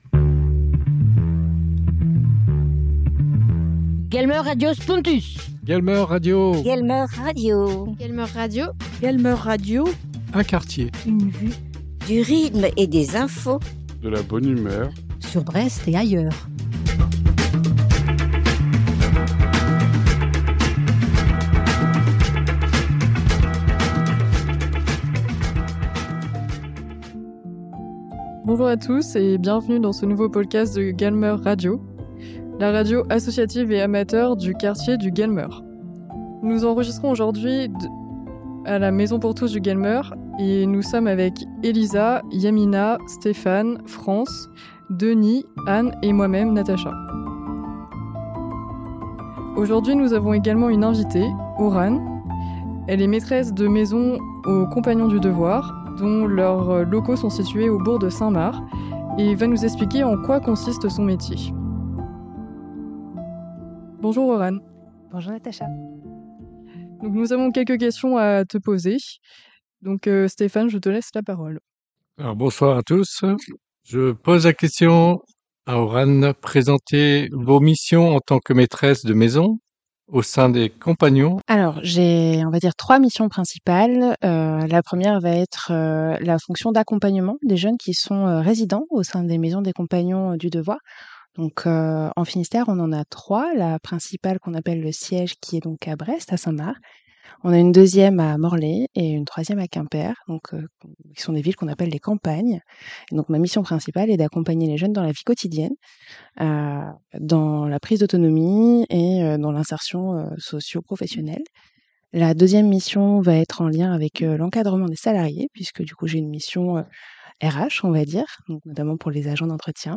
guelmeuradio_emission_mars_compagnons_du_devoir.mp3